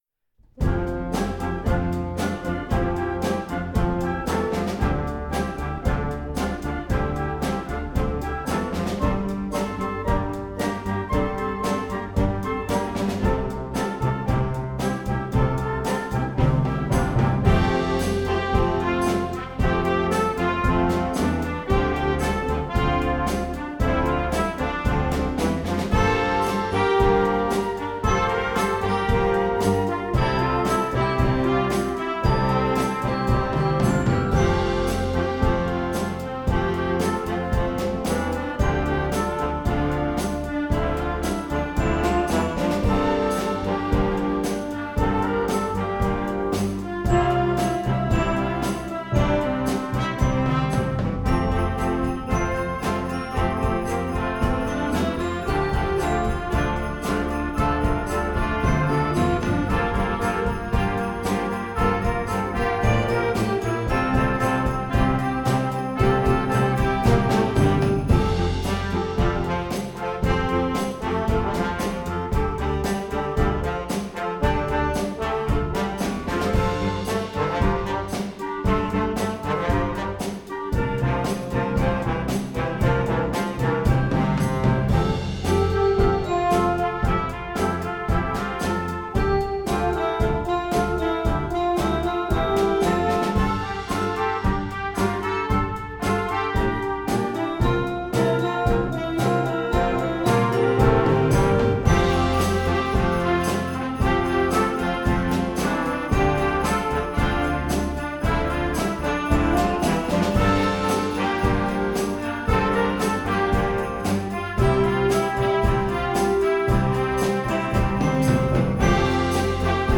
Gattung: Weihnachtslied für Jugendblasorchester
Besetzung: Blasorchester